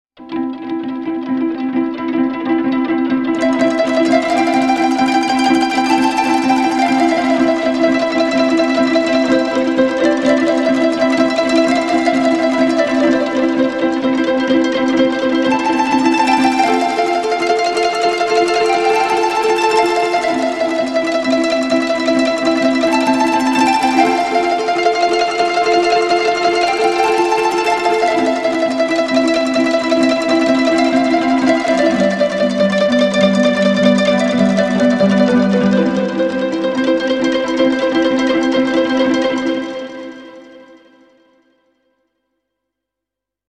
Игра на традиционном японском инструменте кото